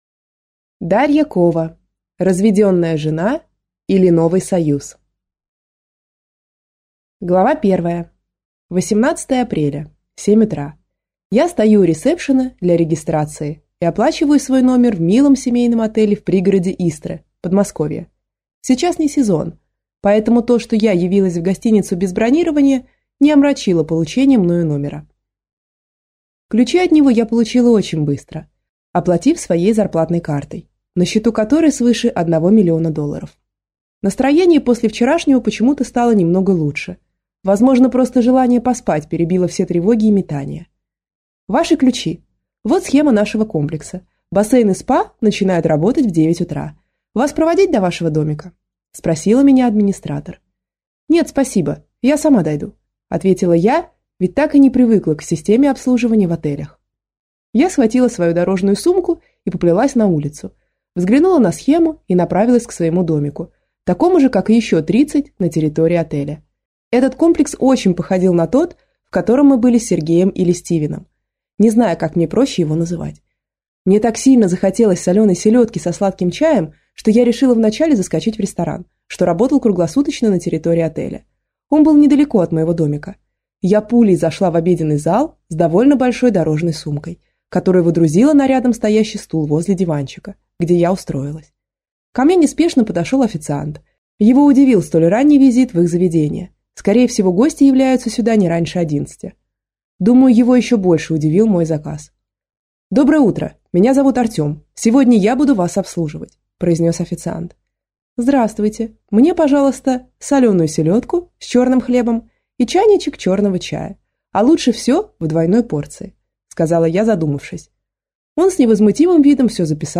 Аудиокнига Разведенная жена, или Новый союз | Библиотека аудиокниг